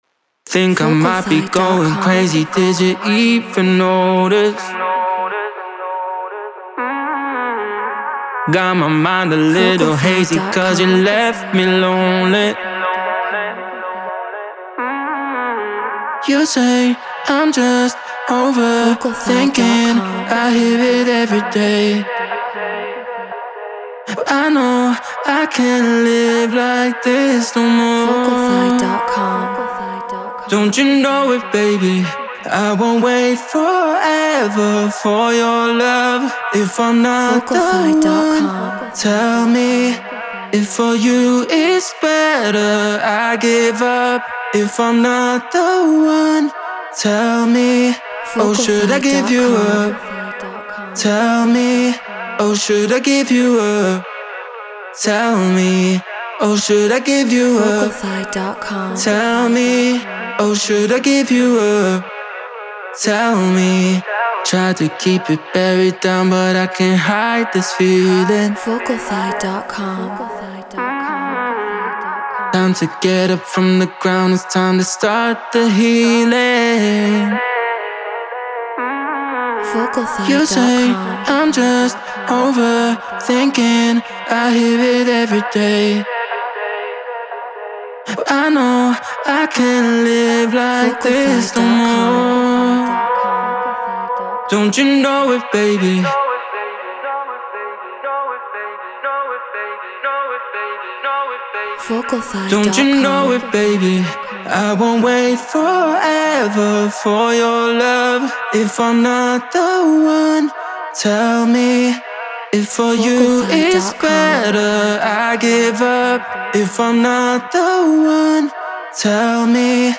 Afro House 120 BPM G#min
Human-Made